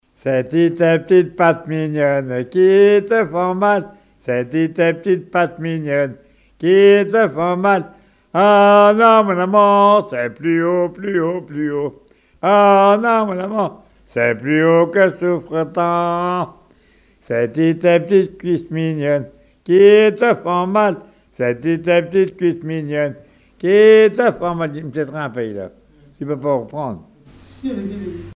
Genre énumérative
chansons et rituel des conscrits
Pièce musicale inédite